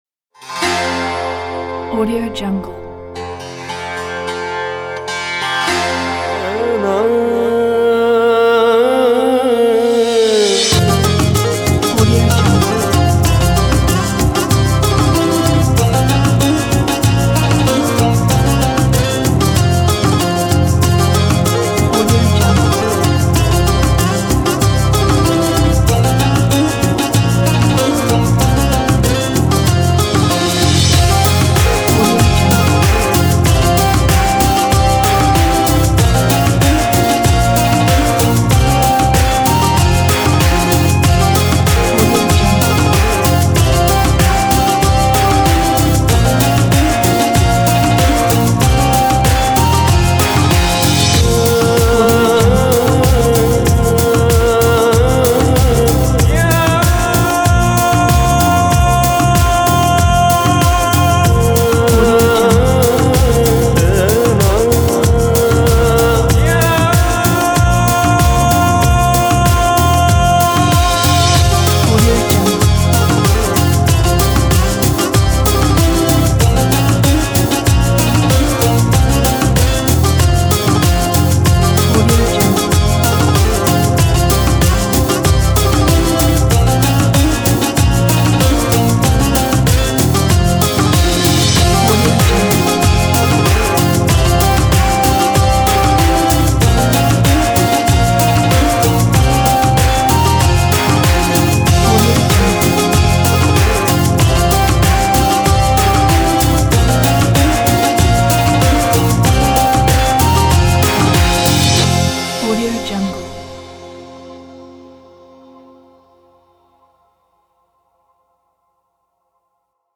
موسیقی جهانی